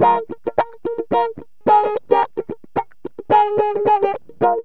GTR 13A#M110.wav